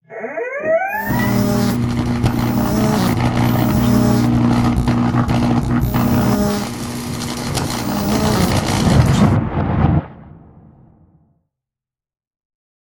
shock.ogg